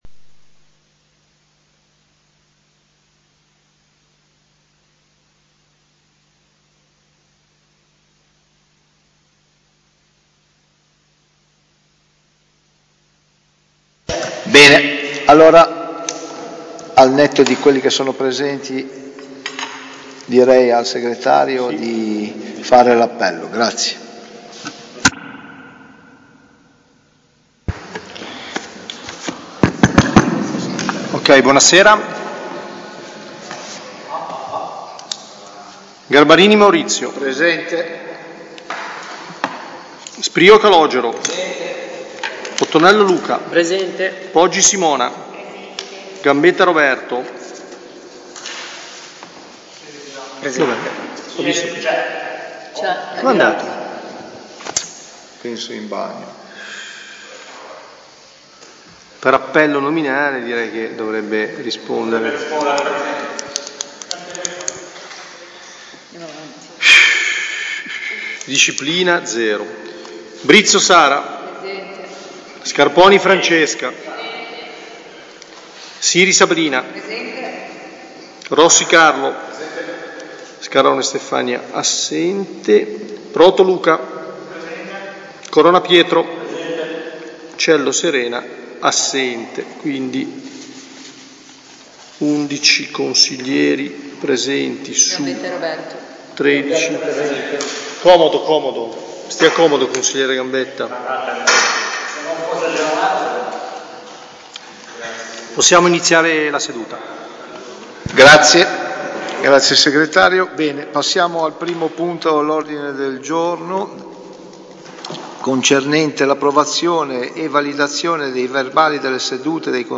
Consiglio Comunale del 7 luglio 2025 - Comune di Albisola Superiore
Seduta del Consiglio comunale lunedì 7 luglio 2025, alle 21.00, presso l'Auditorium comunale in via alla Massa.